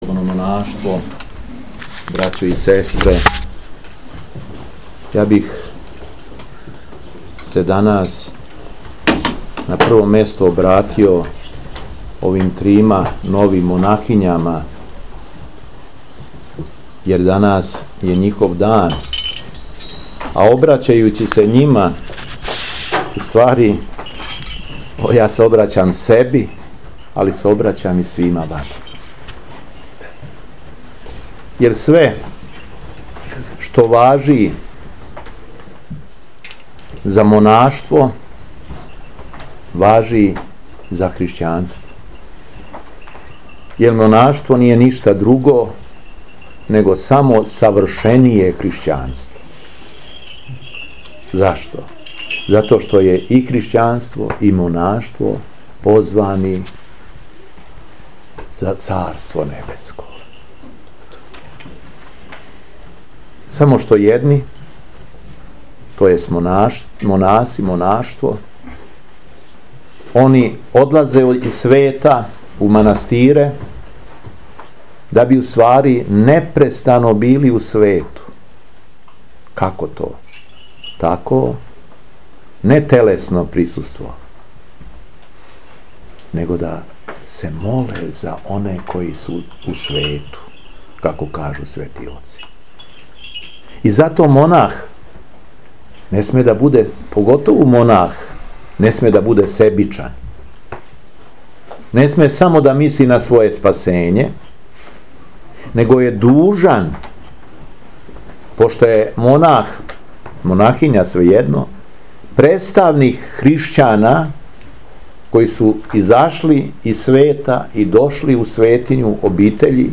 Преосвештени Владика шумадијски Г. Јован, служио је на дан Преподобног Саве Освећеног, Свету Архијерејску Литургију у манастиру Благовештење на Рудник...
Беседа Епископа шумадијског Г. Јована